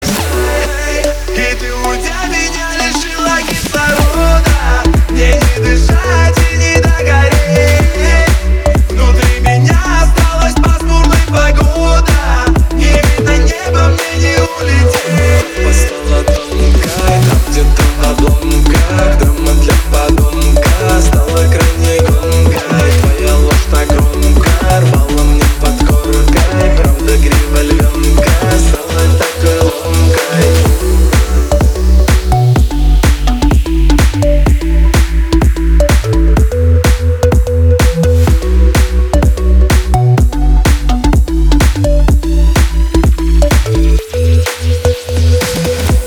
• Качество: 320, Stereo
мужской вокал
громкие
Club House